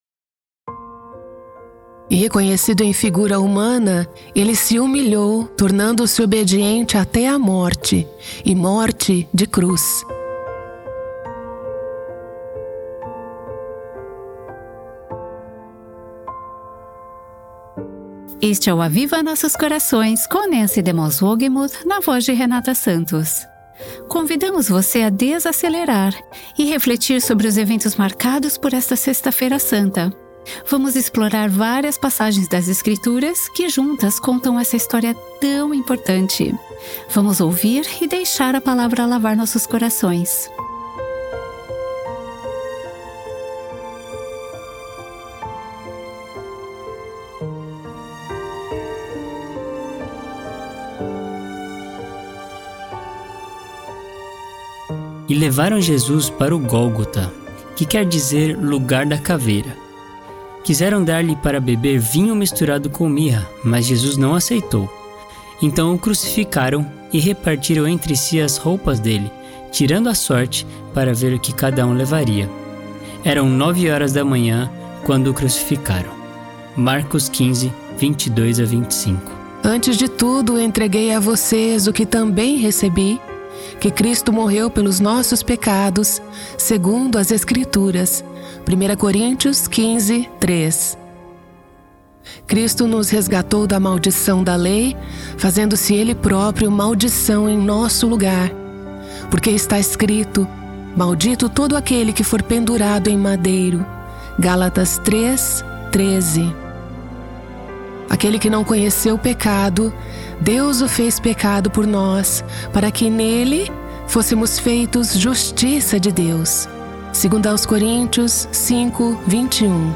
Marque os eventos desse dia ouvindo várias passagens bíblicas.